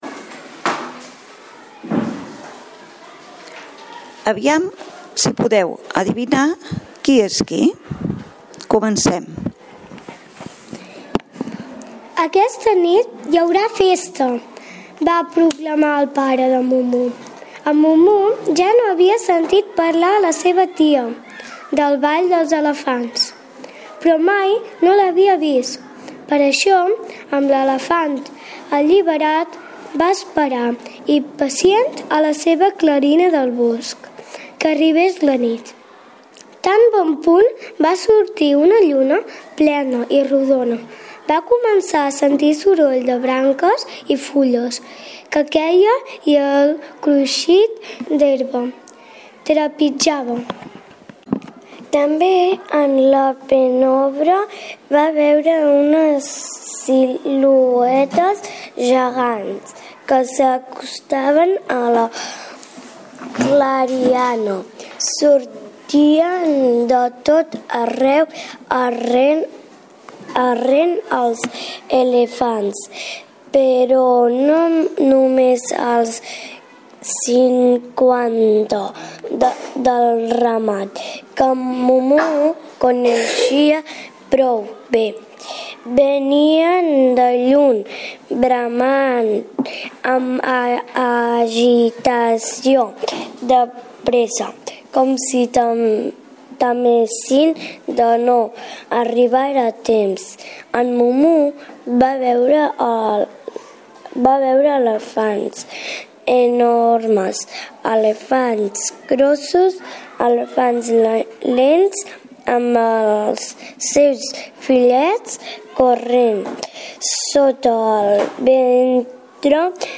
lectura de Mumu